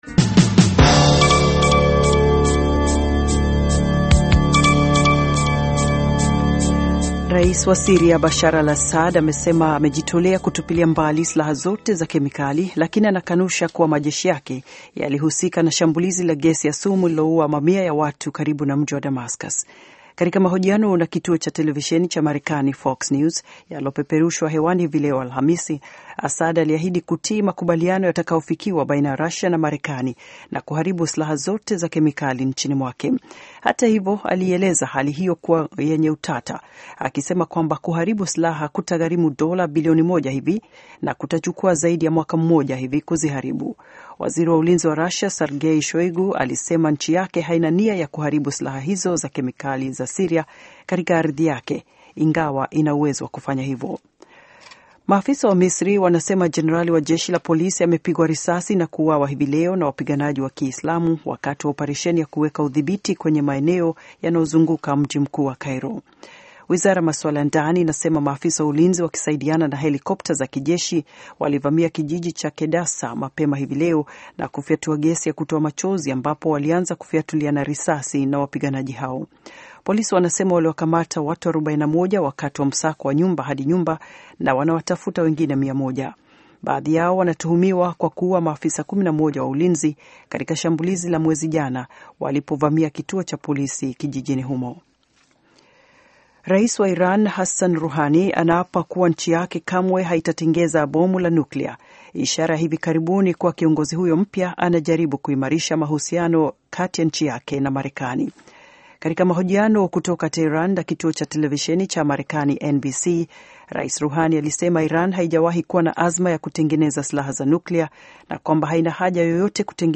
Taarifa ya Habari VOA Swahili - 5:35